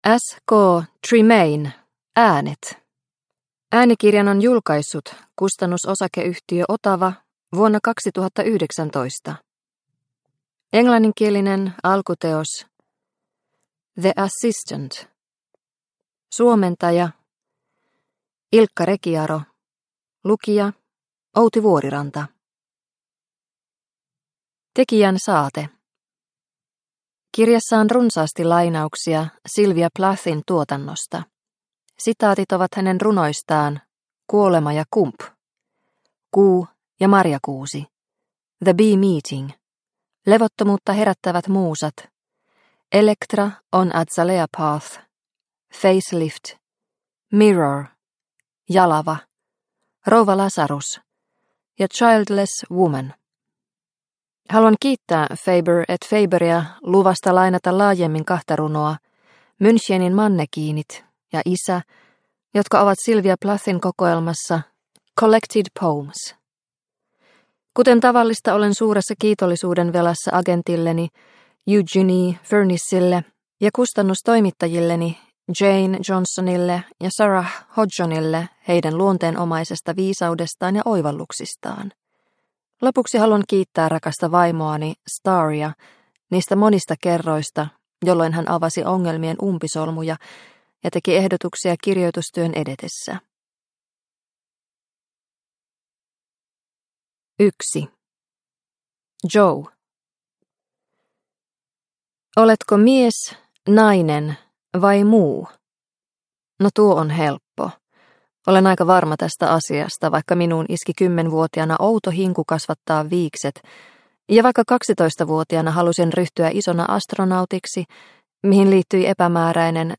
Äänet – Ljudbok – Laddas ner